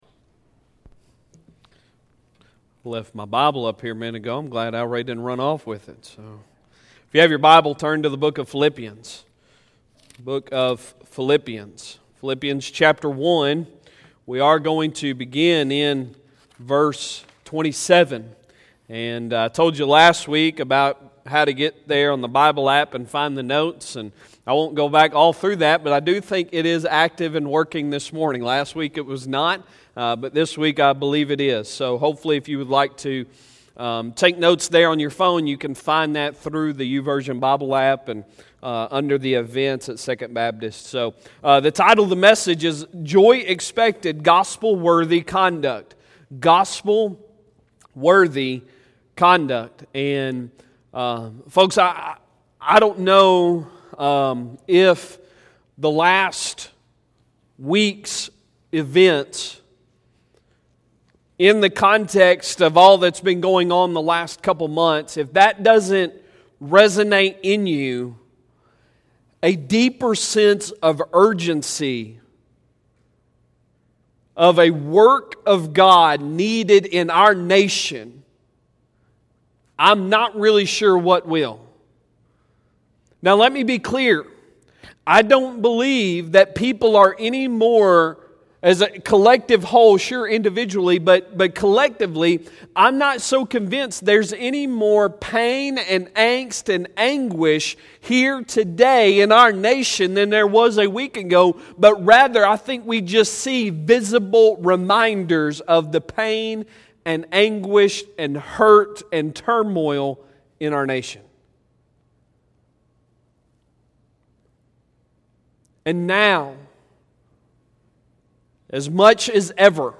Sunday Sermon May 31, 2020